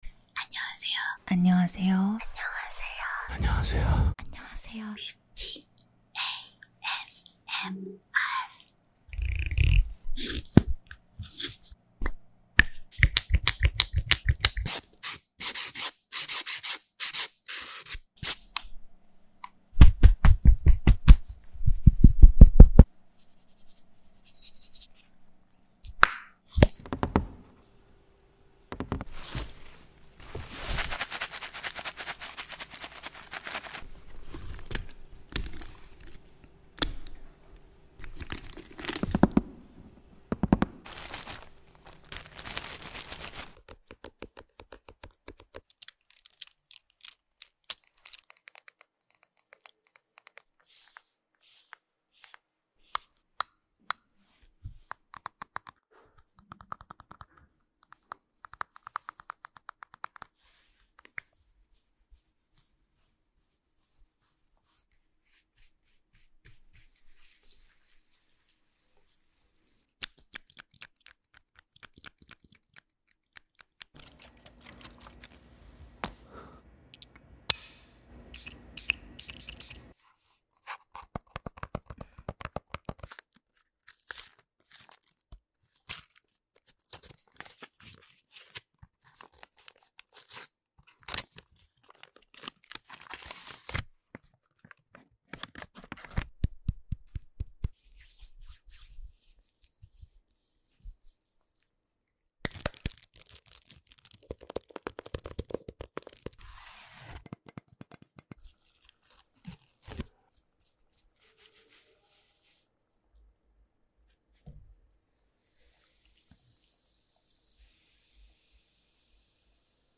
ASMR Beauty with Haper's Bazaar